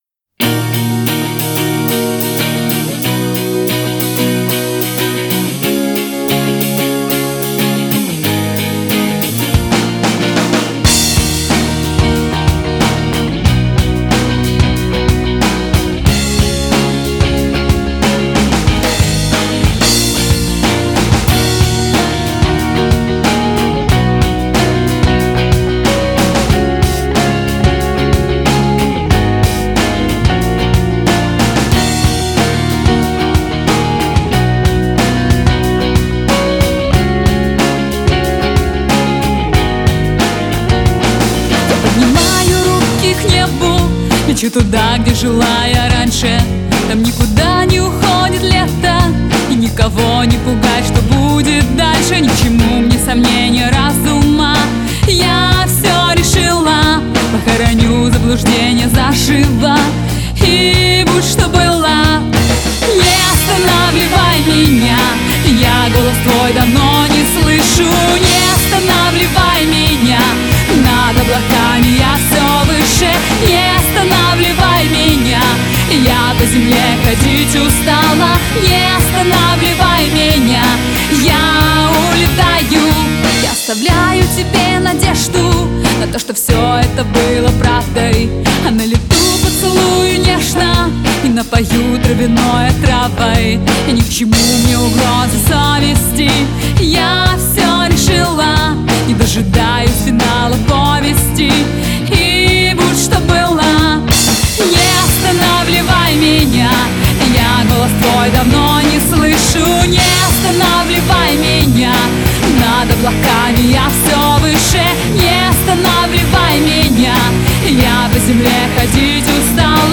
• Жанр: Рок, Русская музыка, Русский рок